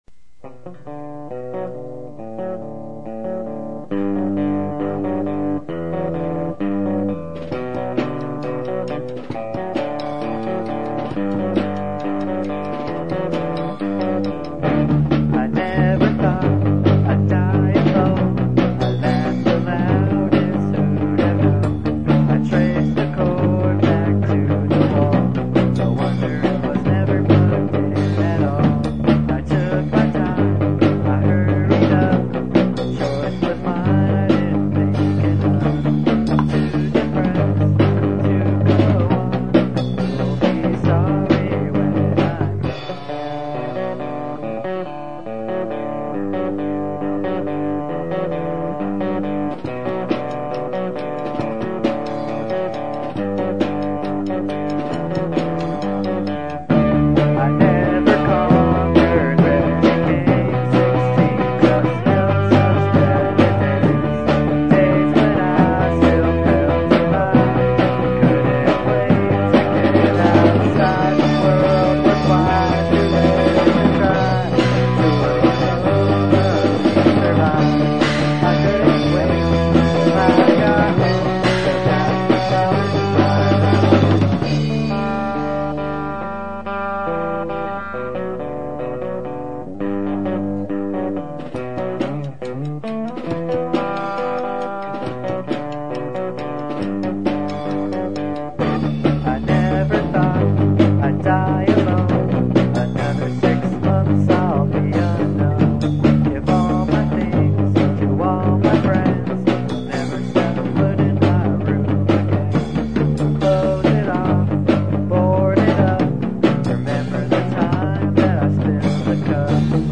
Guitar
Drums
Bass/Vocals